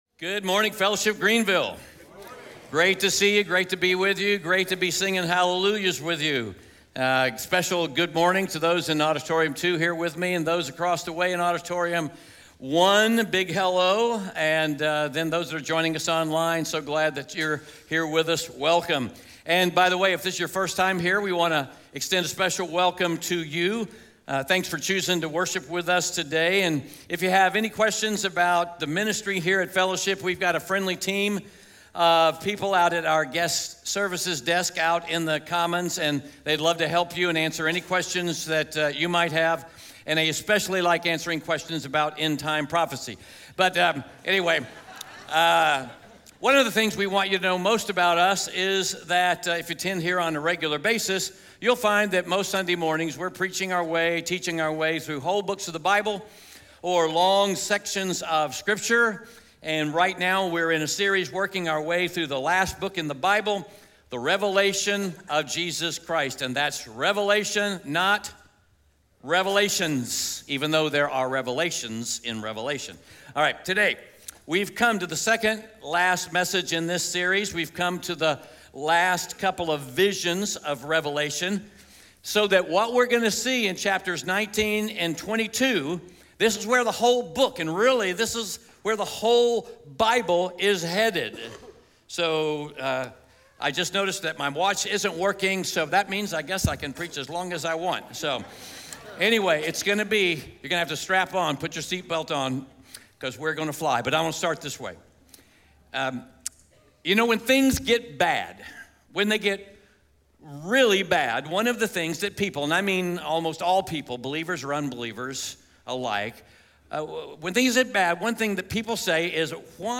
Revelation 19-20 Audio Sermon Notes (PDF) Ask a Question SCRIPTURE: Revelation 19-20 SERMON SUMMARY Today, we come to the second to last message in our Revelation series.